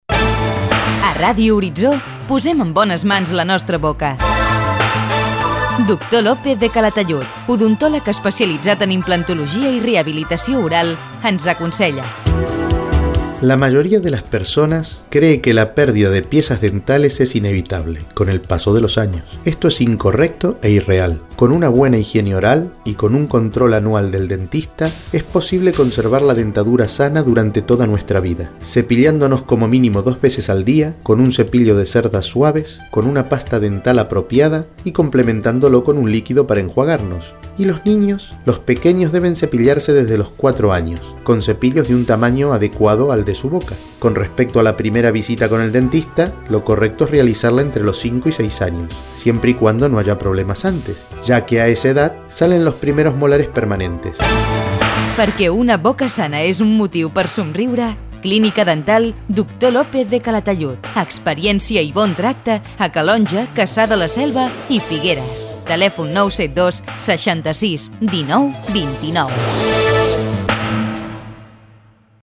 Comercial
Divulgació